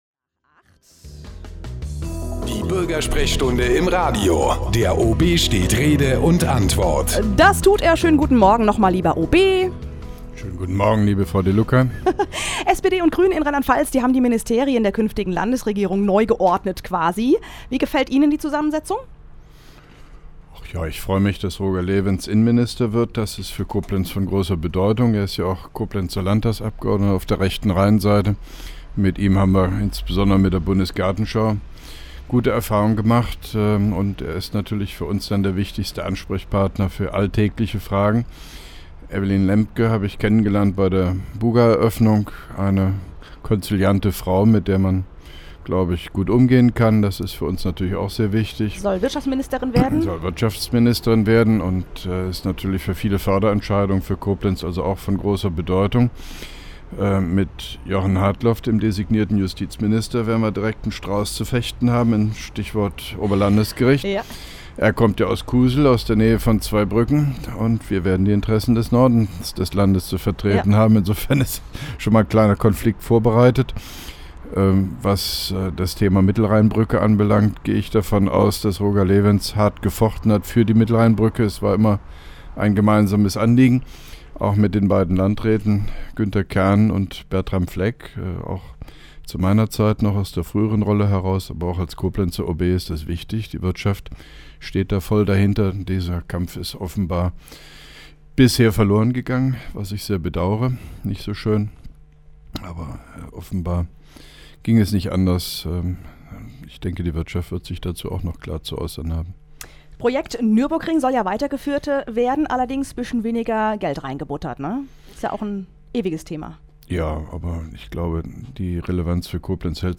(2) Koblenzer Radio-Bürgersprechstunde mit OB Hofmann-Göttig 03.05.2011
Interviews/Gespräche